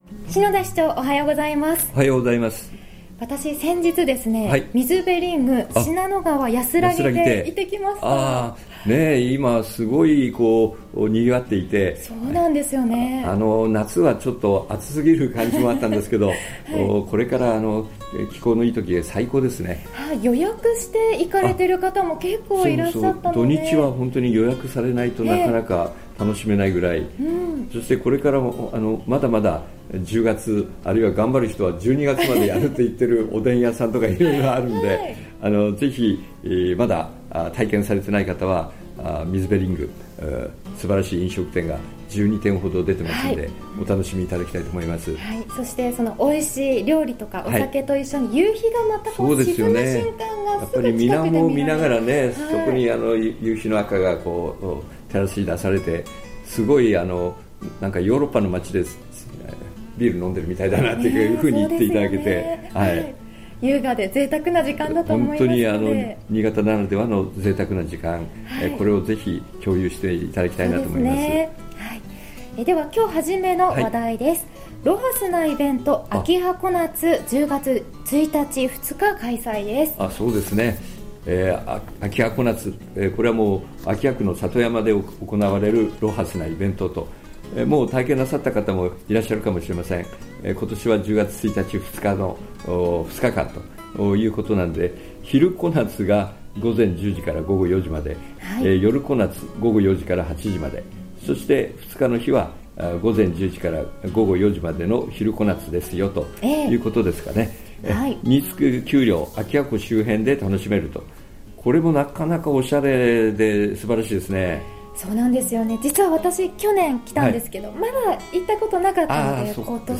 ２０１６年９月２３日（金） 放送分 | 篠田市長の青空トーク